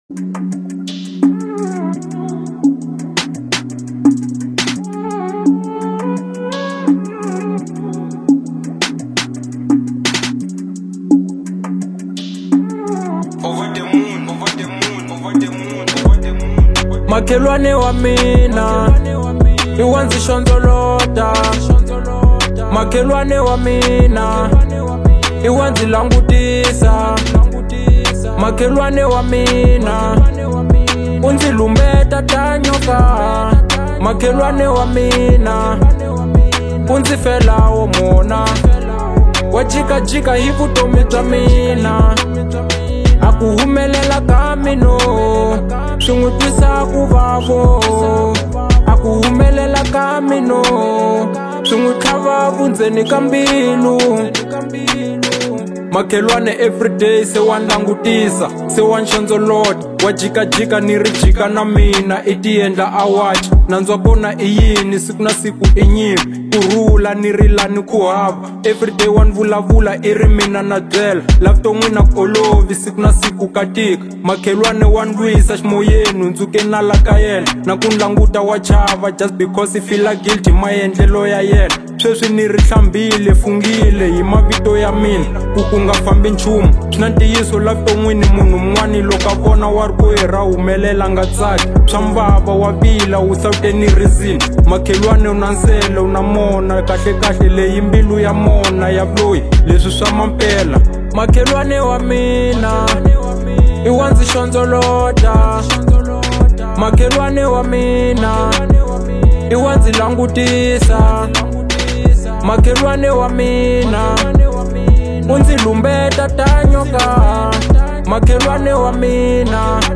03:45 Genre : Hip Hop Size